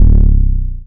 808 (Killer).wav